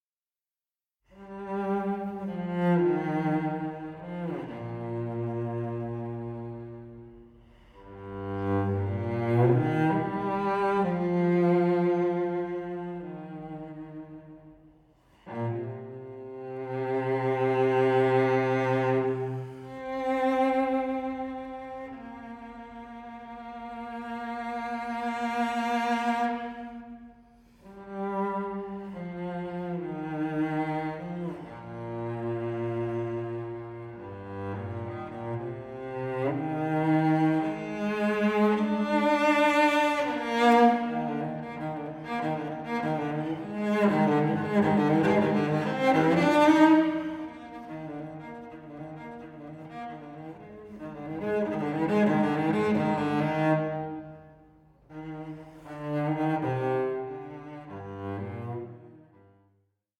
solo cello
merges Spirituals with Bach mock- polyphony